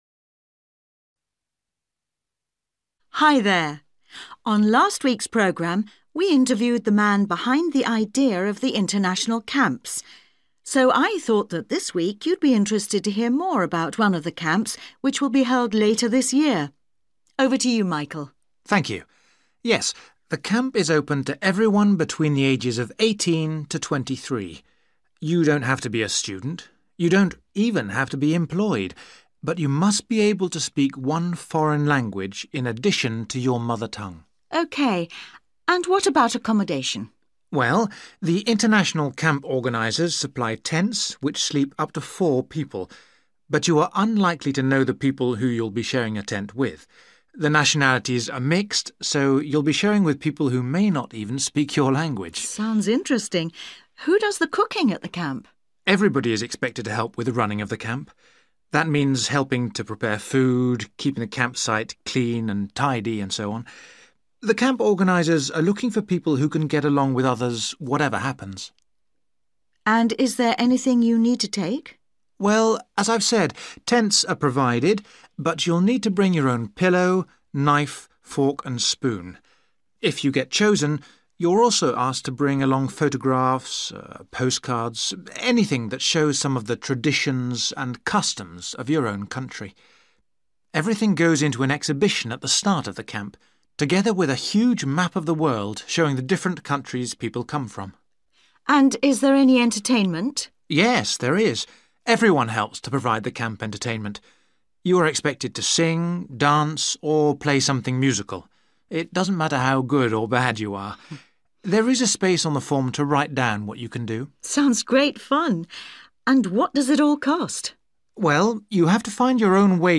You will hear a radio interview with a man who works on an international camp.